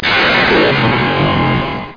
02091_Sound_PowerUp.mp3